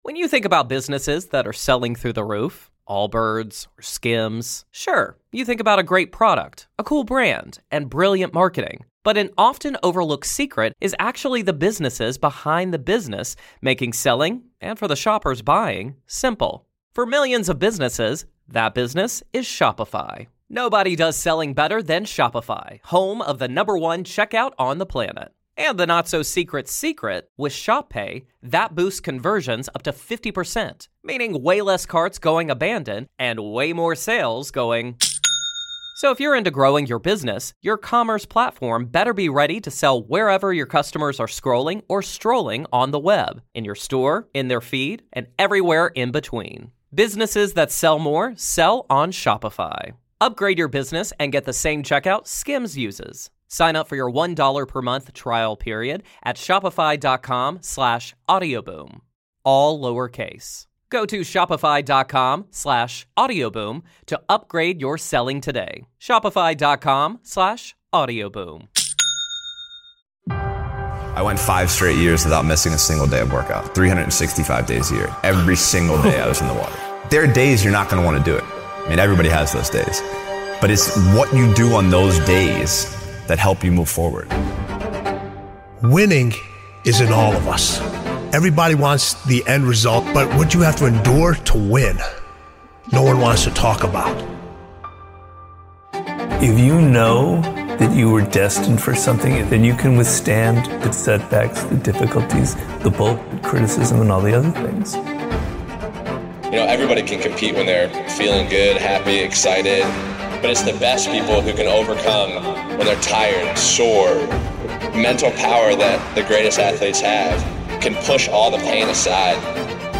It's when they're tired is when the real champions come out. featuring speeches from Michael Phelps, Serena Williams, Patrick Mouratoglou, Aaron Rodgers, Steph Curry, Tim Grover, and more.